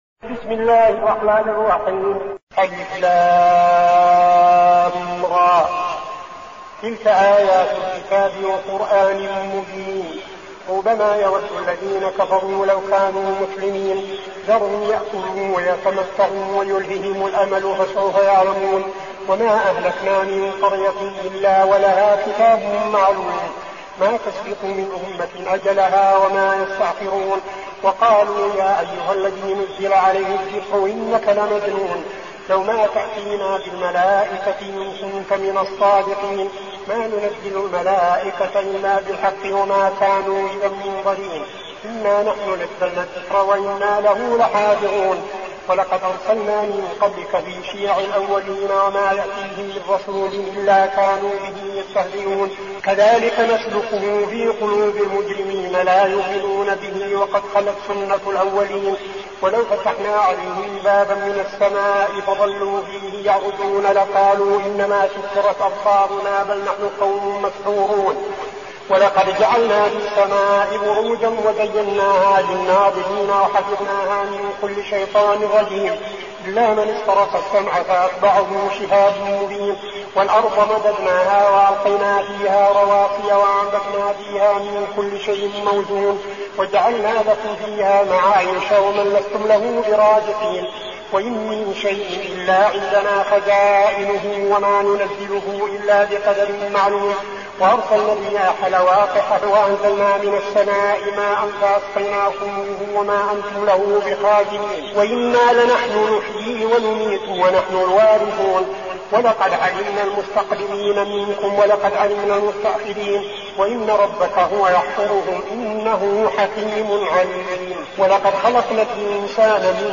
المكان: المسجد النبوي الشيخ: فضيلة الشيخ عبدالعزيز بن صالح فضيلة الشيخ عبدالعزيز بن صالح الحجر